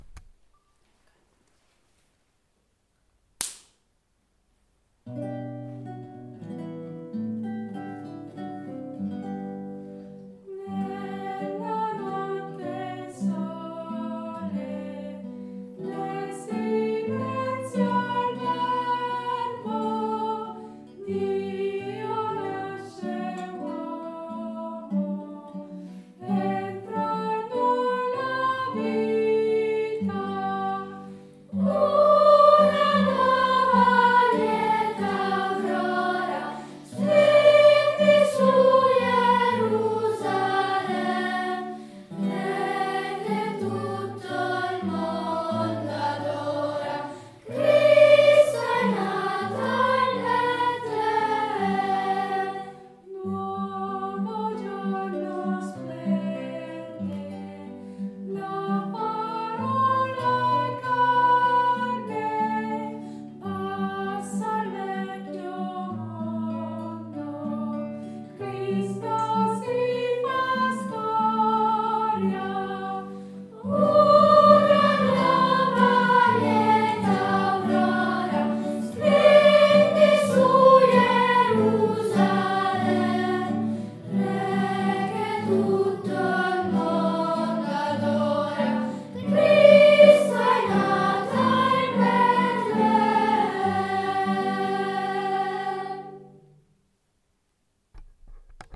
La melodia è intuitiva (ma tutt’altro che banale) e si presta a essere usata per celebrare la gioia e la speranza.
Nella-notte-il-sole-Chitarra-e-voci-bianche.mp3